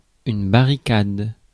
Prononciation
Prononciation France: IPA: /ba.ʁi.kad/ Le mot recherché trouvé avec ces langues de source: français Traduction 1.